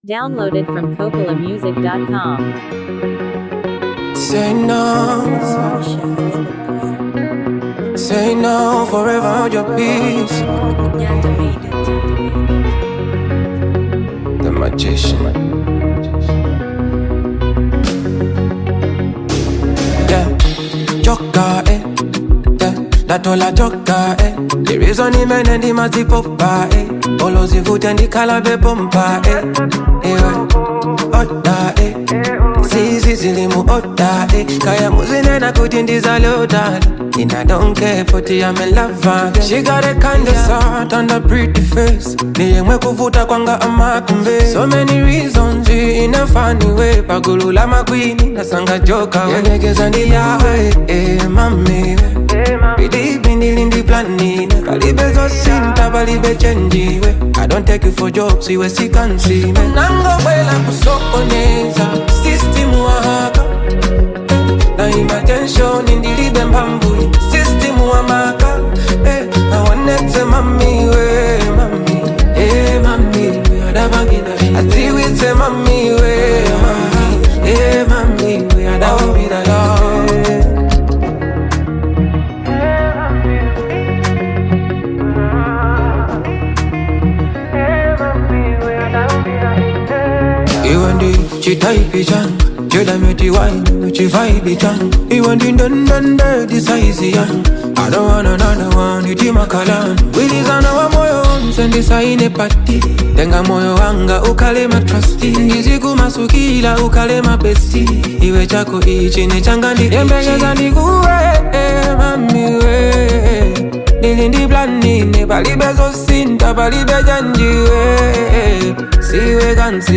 Afro-pop love song
The production is soft and melodic